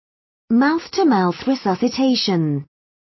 Mouth-to-mouth_resuscitation.wav